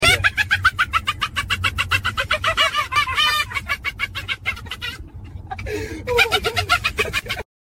Heyena Laughing
Heyena-Laughing.mp3